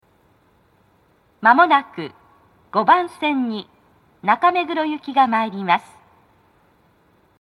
鳴動は、やや遅めでした。
女声
接近放送1